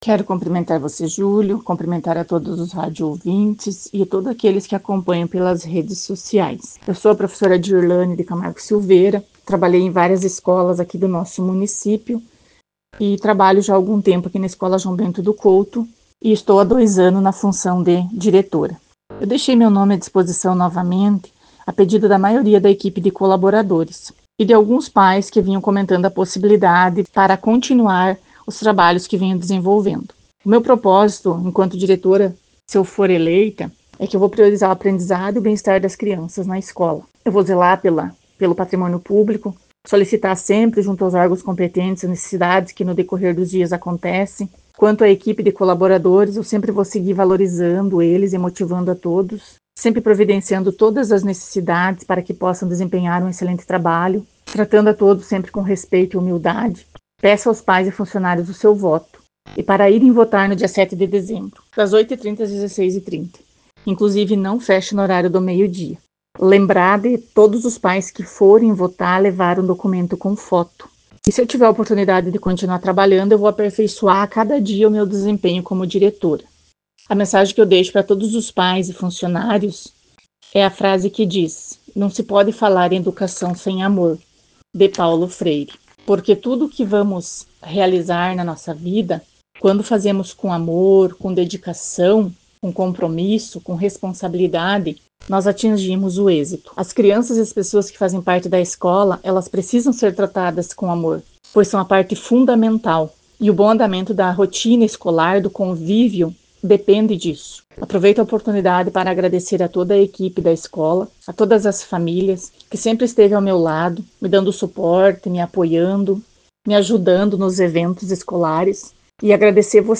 Ela conversou com a nossa reportagem sobre sua candidatura e como pretende atuar na instituição.